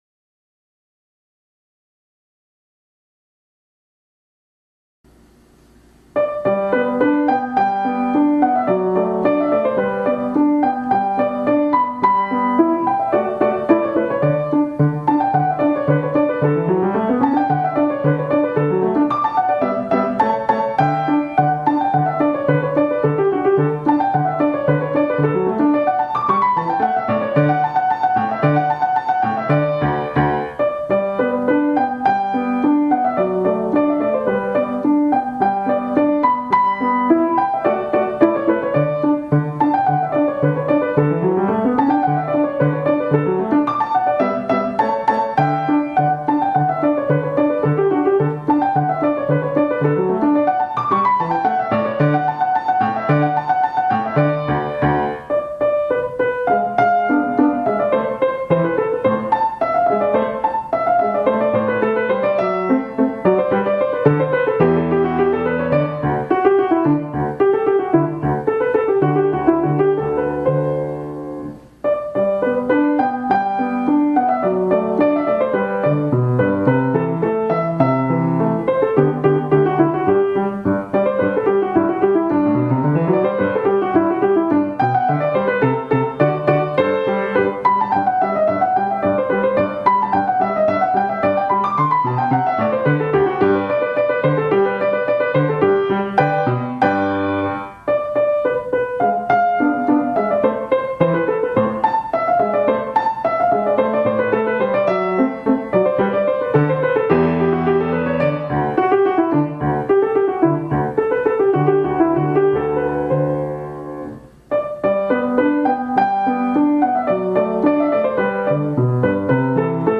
nauka gry na fortepianie